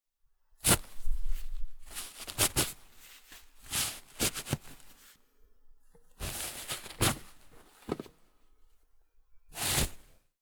tissue-pull.wav